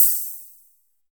FILTER OHH.wav